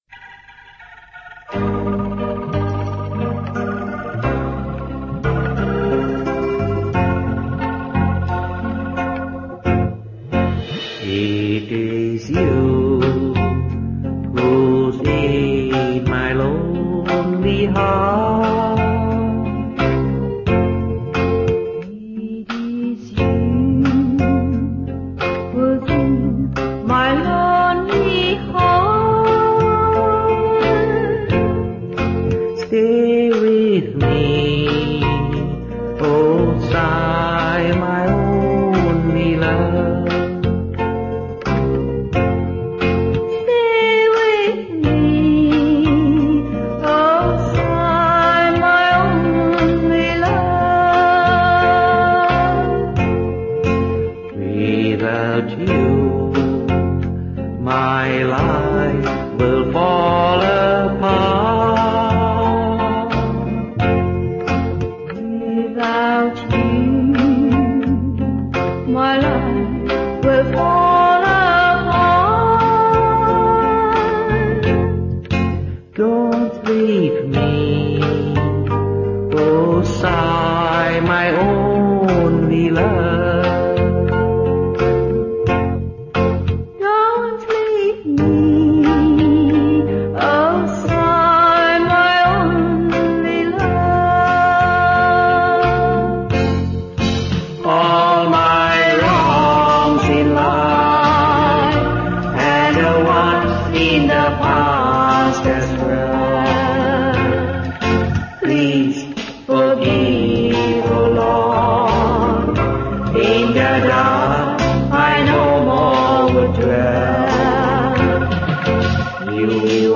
1. Devotional Songs
Major (Shankarabharanam / Bilawal)
8 Beat / Keherwa / Adi
Medium Fast